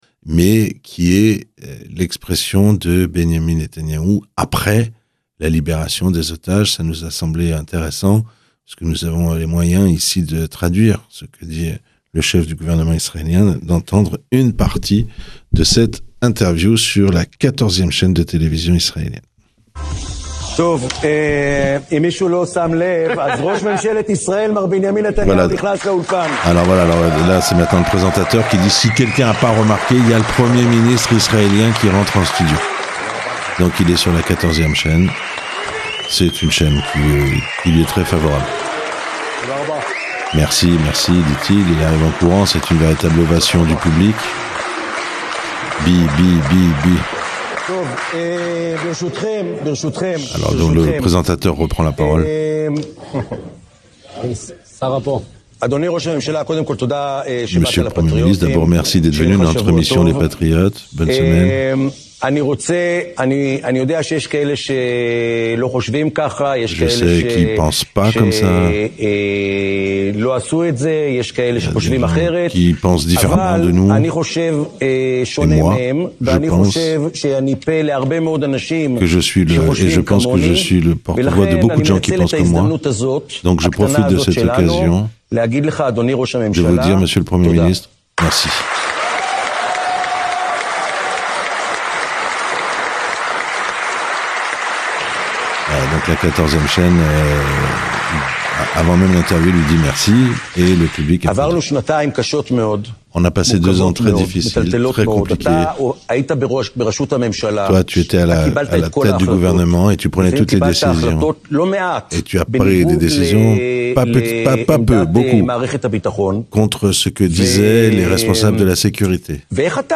Ecoutez la première interview de Netanyahou aprés la guerre (traduite en français)